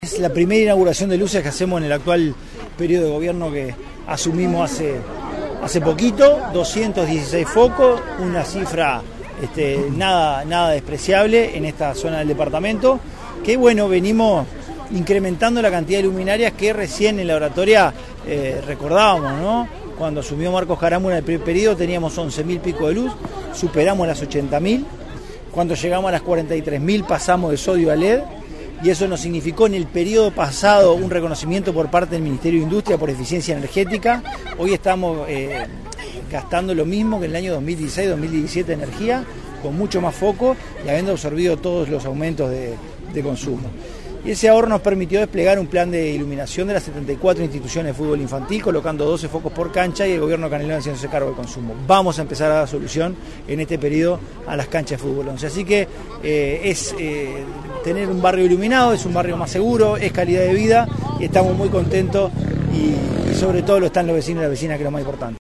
El Intendente Francisco Legnani, reiteró el compromiso con la iluminación de los clubes de fútbol a los que alcanzará el plan de alumbrado, incluyendo el "futbol 11" en todo el departamento.